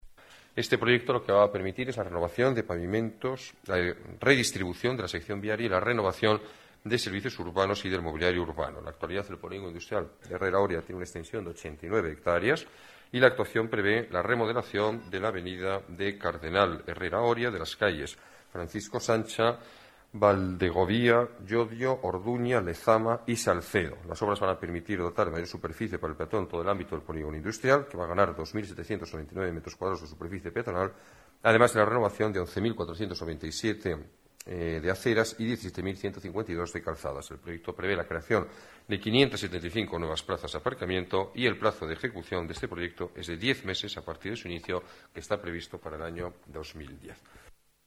Nueva ventana:Declaraciones del alcalde, Alberto Ruiz-Gallardón: Polígono Herrera Oria